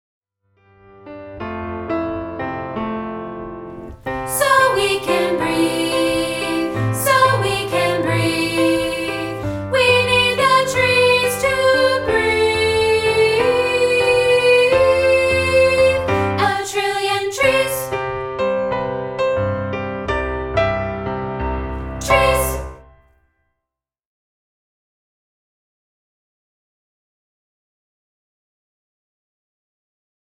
This is a rehearsal track of part 3, isolated.